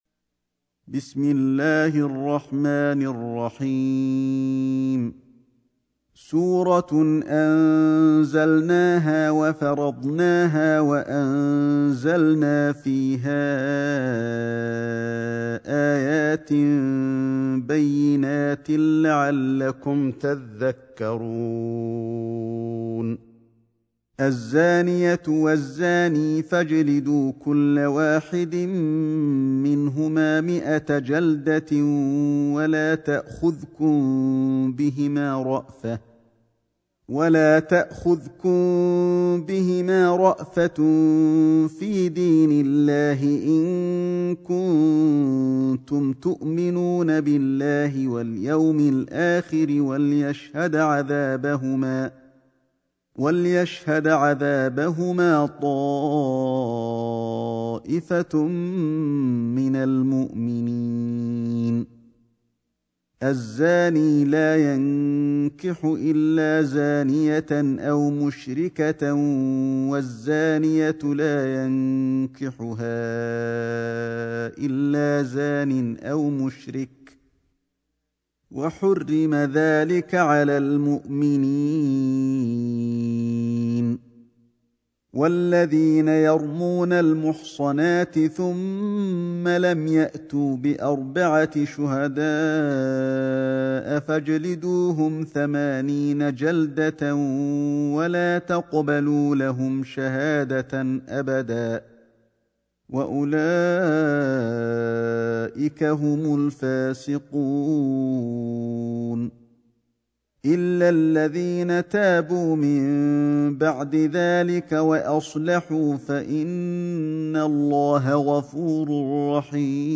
سورة النور > مصحف الشيخ علي الحذيفي ( رواية شعبة عن عاصم ) > المصحف - تلاوات الحرمين